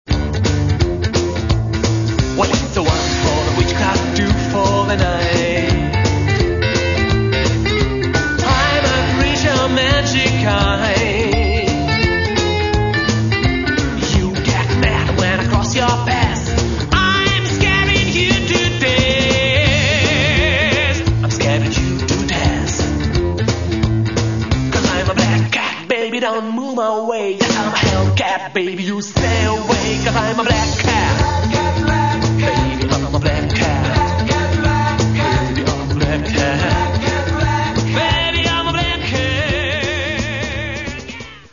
Каталог -> Рок и альтернатива -> Сборники